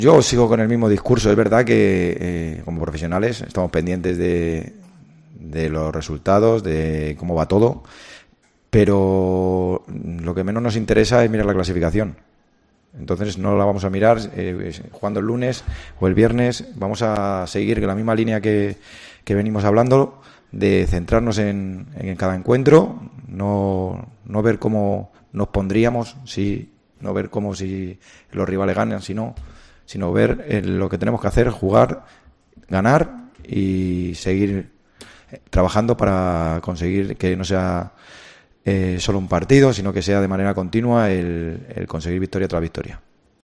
Calleja, en la previa del Levante UD-Racing de Ferrol